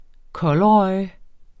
Udtale [ ˈkʌl- ]